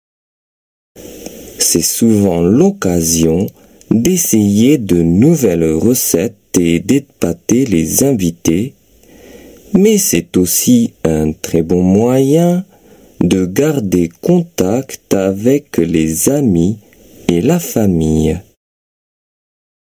先日トレビアンでやったディクテに、以下のような文章がありました。